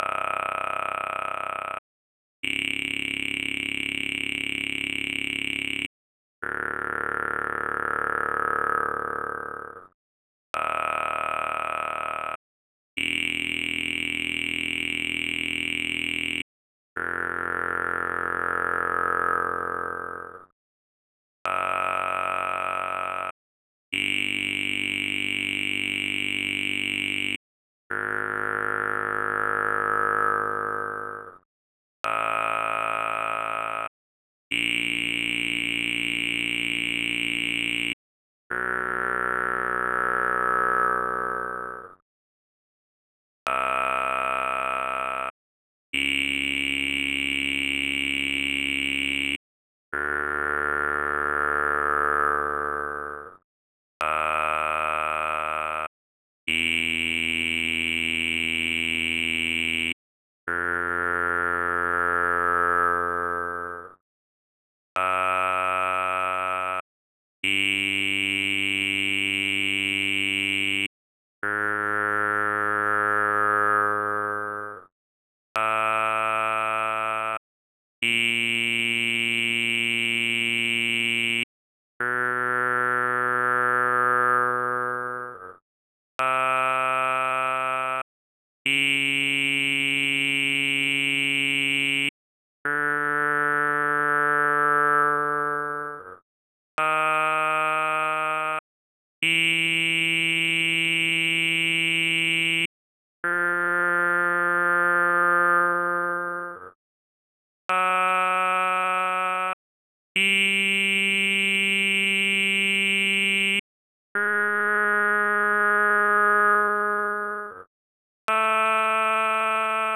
B-T unfiltered chromatics.wav